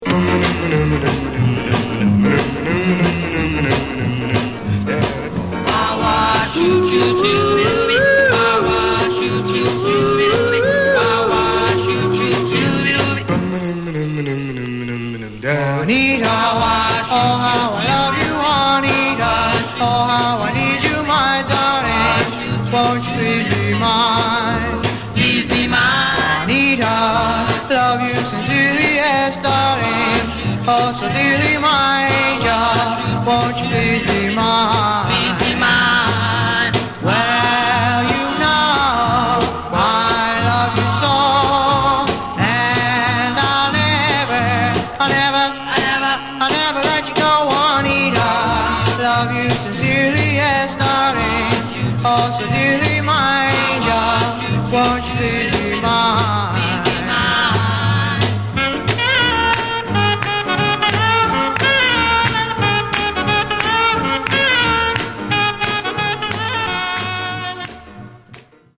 Genre: Vocal Groups (Doo-Wop)
late 1950's uptempo Doo-Wop